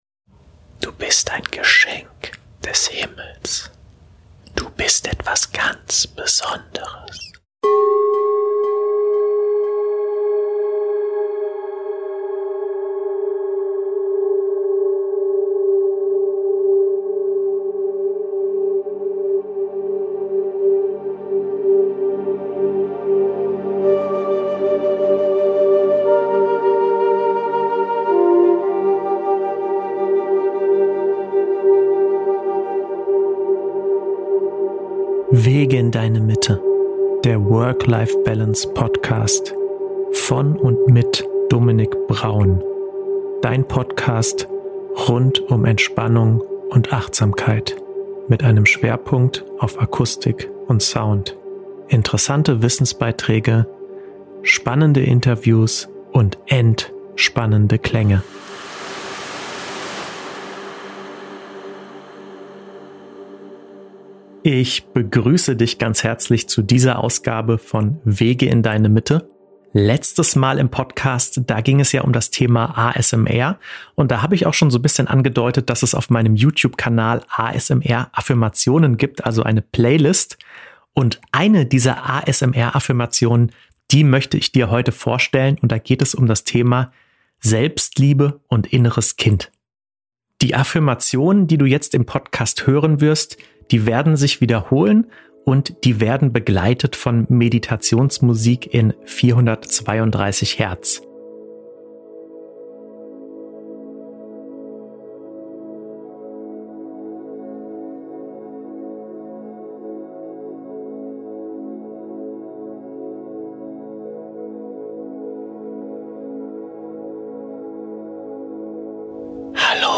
ASMR-Affirmation zum Thema Selbstliebe und Inneres Kind (Episode 16) ~ Wege in Deine Mitte Podcast
Hierbei kombiniere ASMR mit klassischen Affirmationen. Die Affirmationen in dieser Podcast-Folge drehen sich rund um die Themen Selbstliebe und Inneres Kind.